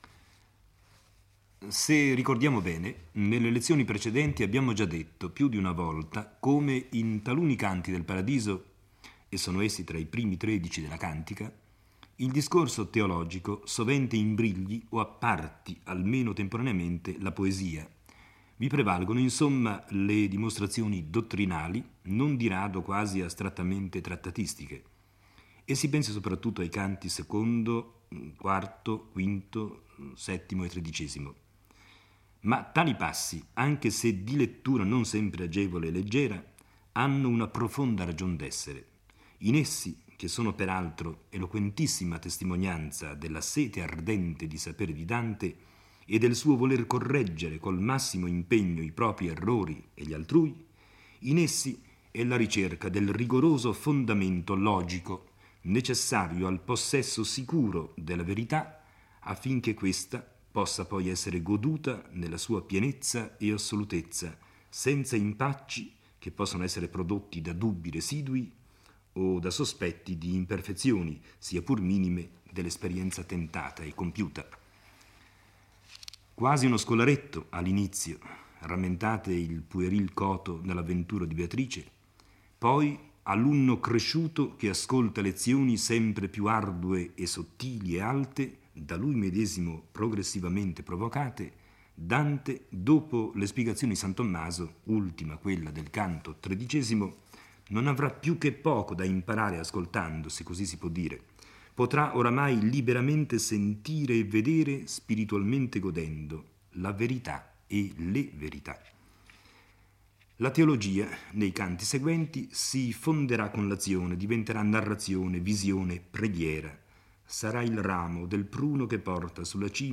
legge e commenta il XIV canto del Paradiso. Salomone spiega al poeta che la luce durerà in eterno con una intensità proporzionale al merito di ciascuna anima: dopo la resurrezione i beati - riunita l'anima al corpo - si troveranno in uno stato di maggiore perfezione.